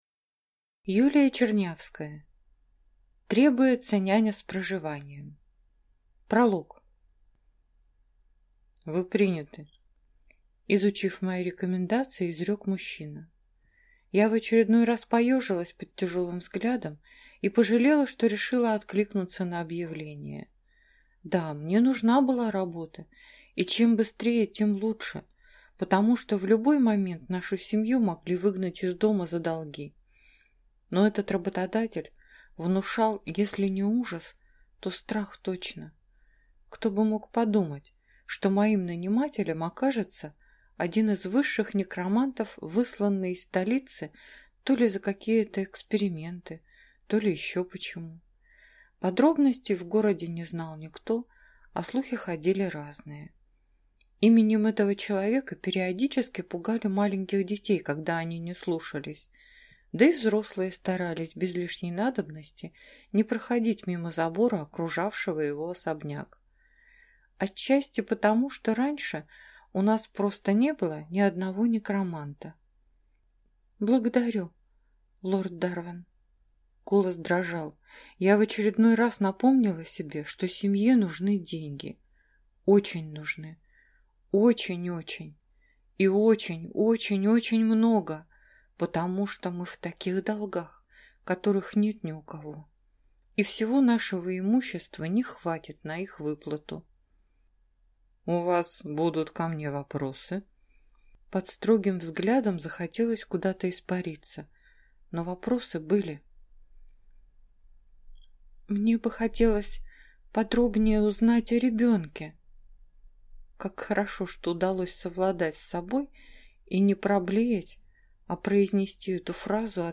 Аудиокнига Требуется няня с проживанием | Библиотека аудиокниг